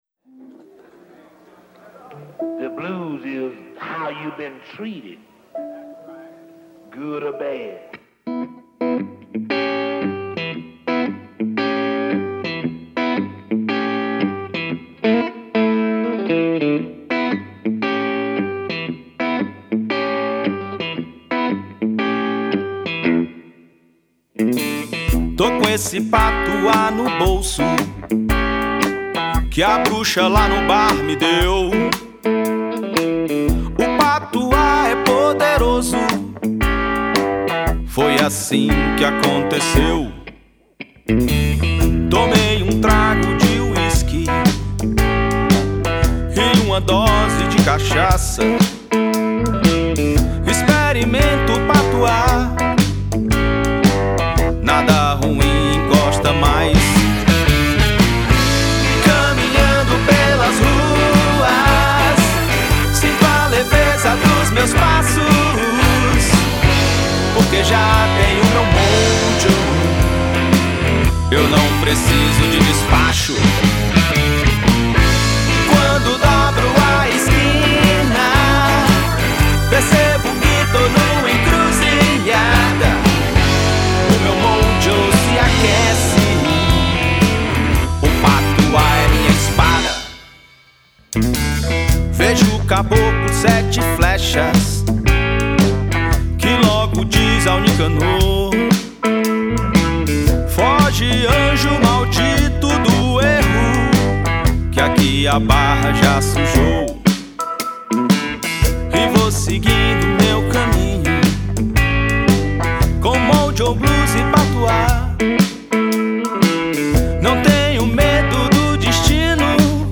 2261   04:34:00   Faixa:     Jazz